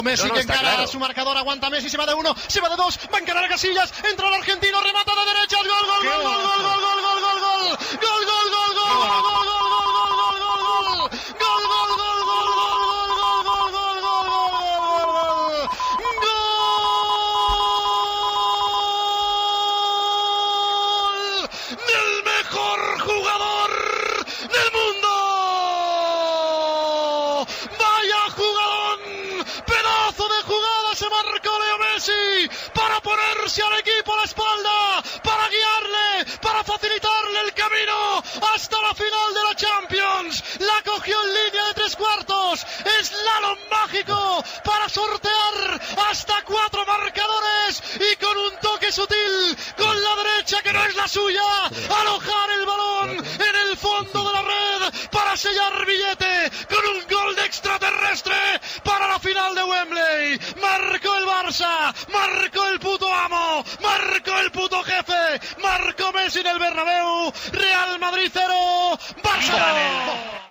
Narració del gol de Leo Messi al partit d'anarda de semifinals de la Copa d'Europa de futbol masculí entre el Real Madrid i el Futbol Club Barcelona disputat a l'Estadio Santiago Bernabeu
Esportiu